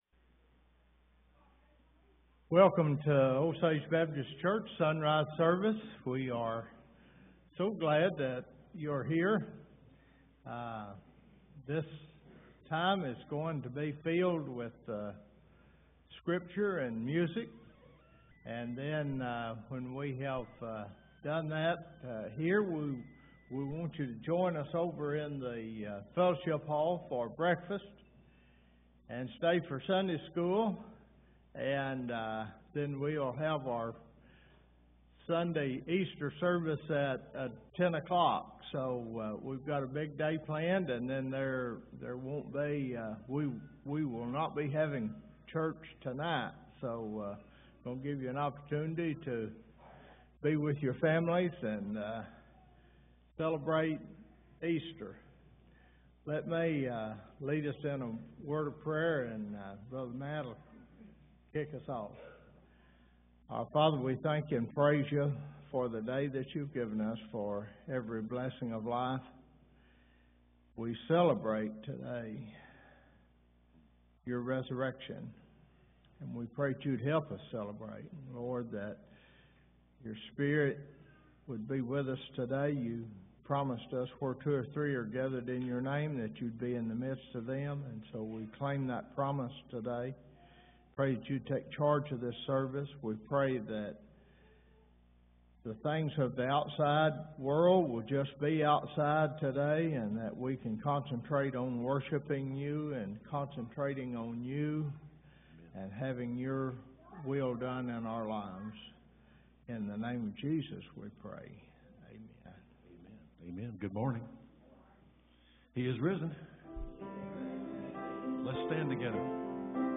04/09/23 Easter Sonrise Service Audio